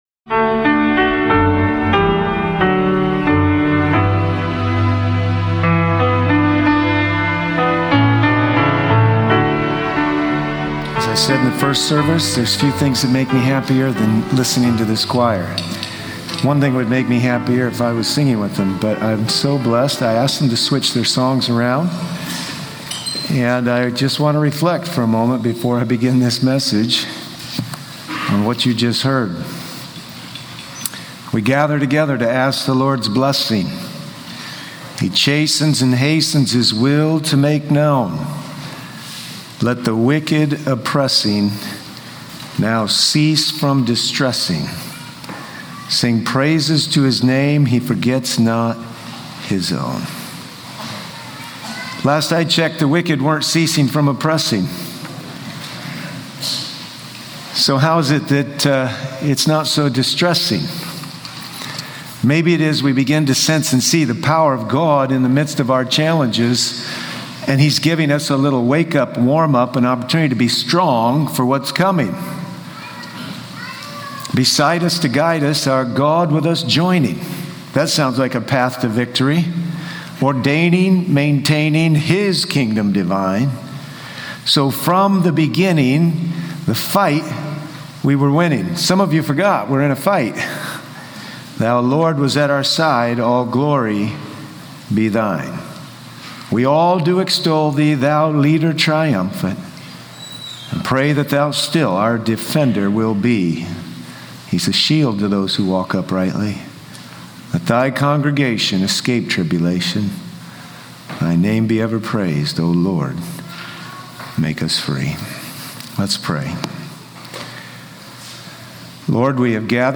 Worship is more than just music—it’s a fortress of faith that unites believers, strengthens the church, and serves as a testimony to the world. This powerful sermon explores the role of worship, spiritual resilience in adversity, and the call to actively live out our faith, ensuring that grace transforms lives and the church remains a beacon of hope.